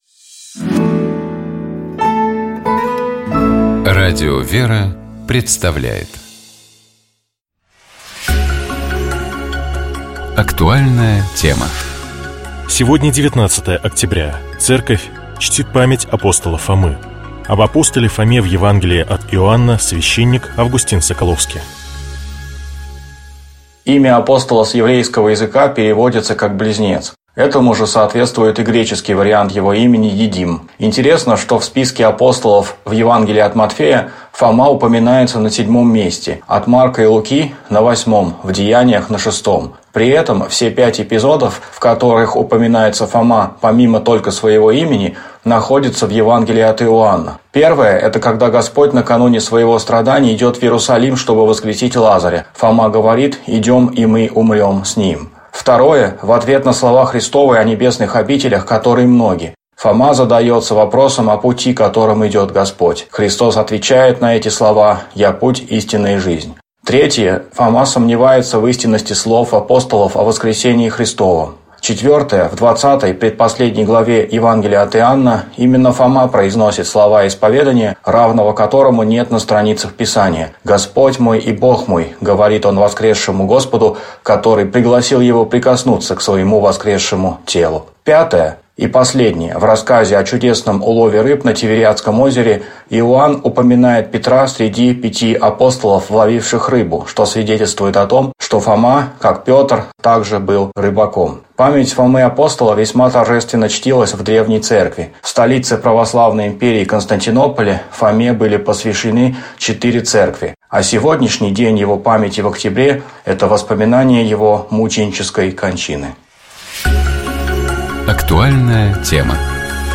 священник